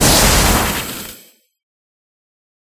Ice11.ogg